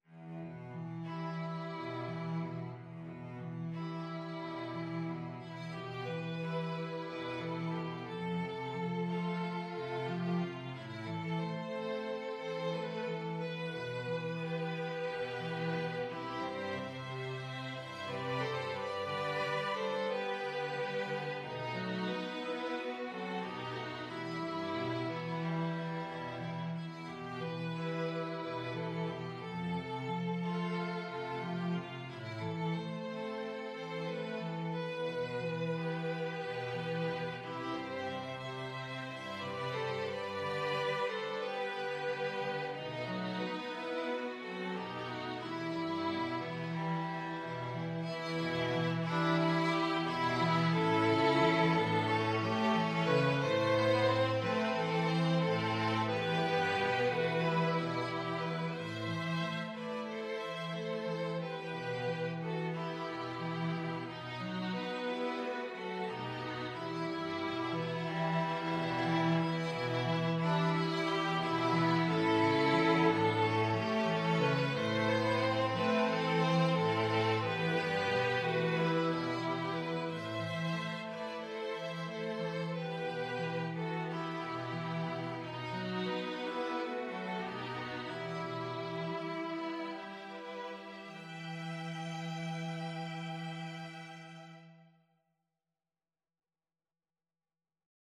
Violin 1Violin 2ViolaCello
4/4 (View more 4/4 Music)
Gently Flowing =c.90
Classical (View more Classical String Quartet Music)